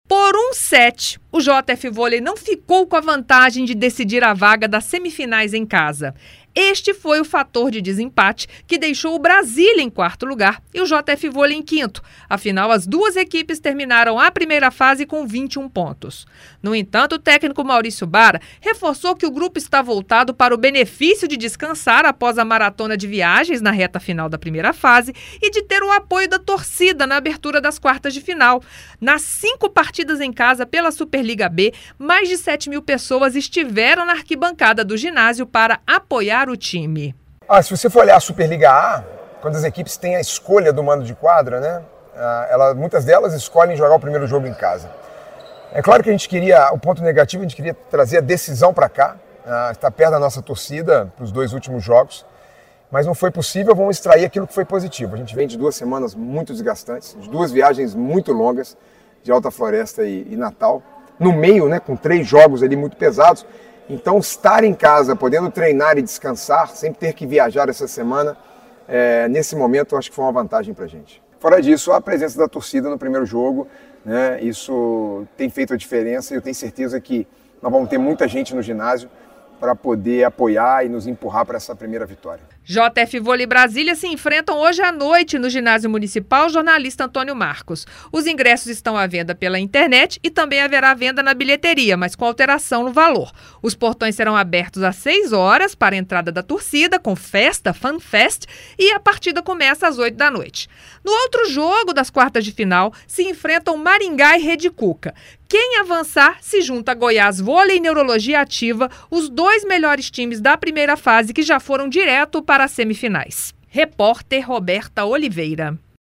em entrevistas